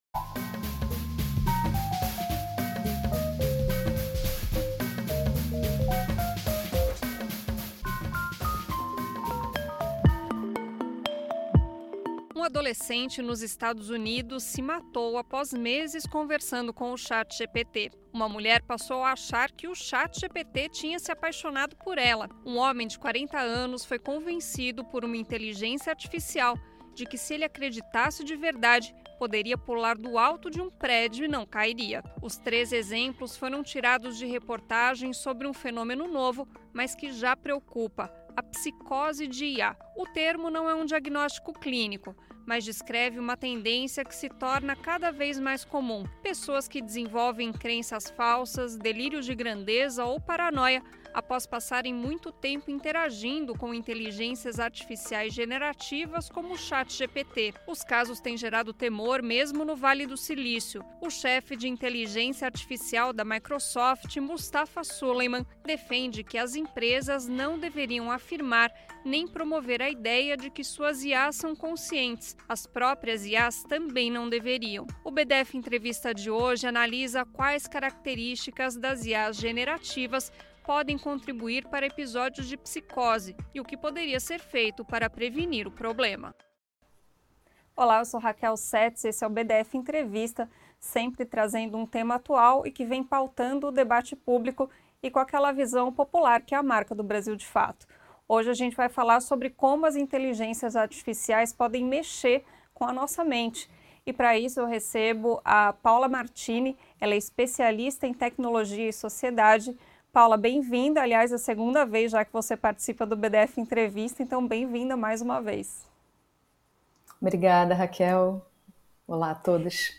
Áudio da entrevista